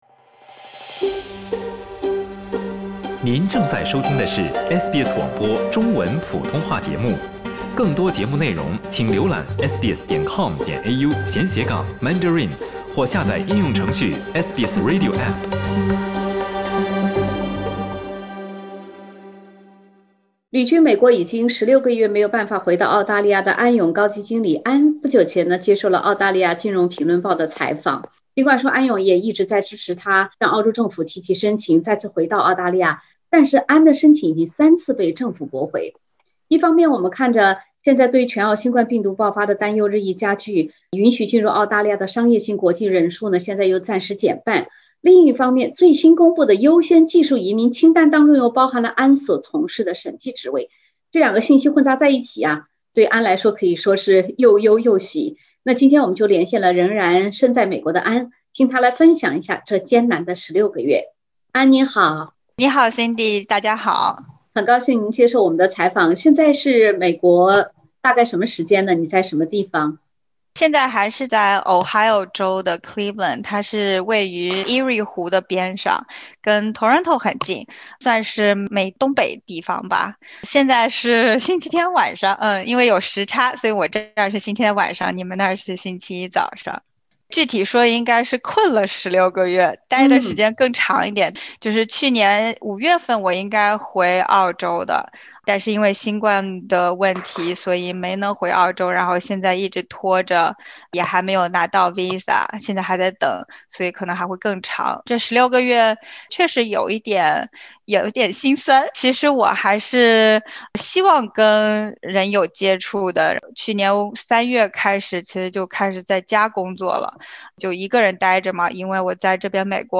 下面请听本台记者带来的采访。